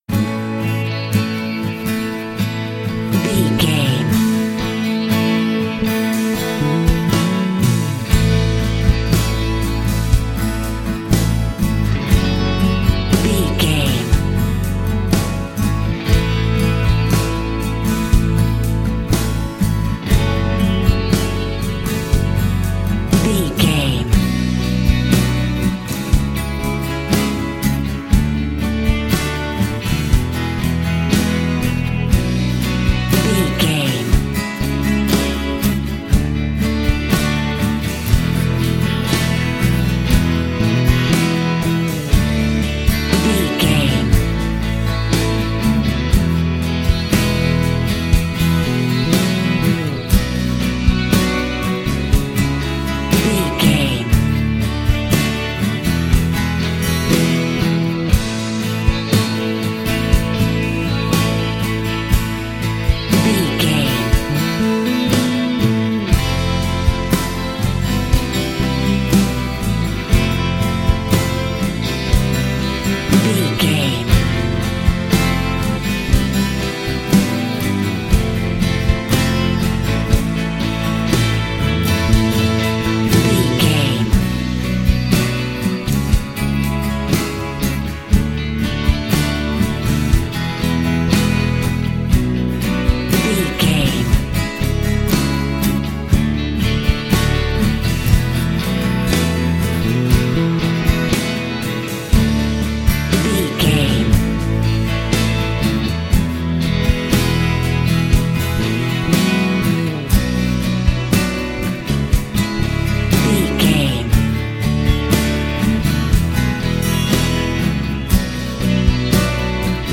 Ionian/Major
Slow
electric guitar
drums
bass guitar
acoustic guitar